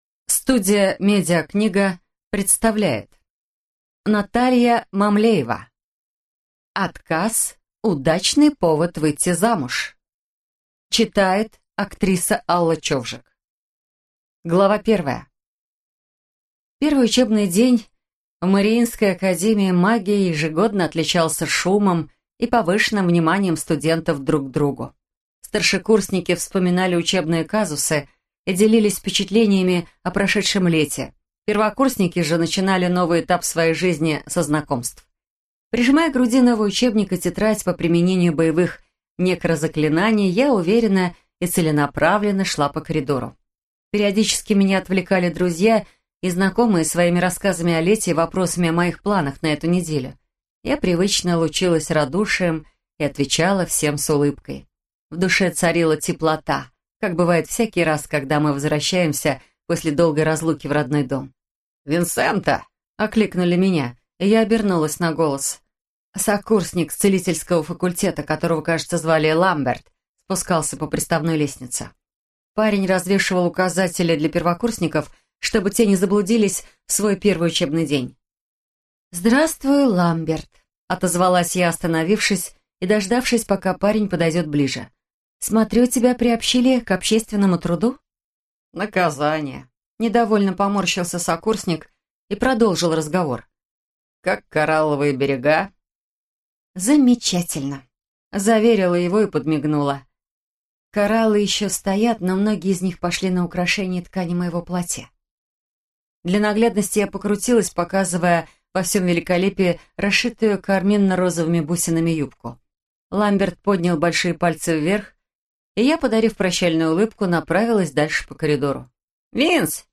Аудиокнига Отказ – удачный повод выйти замуж!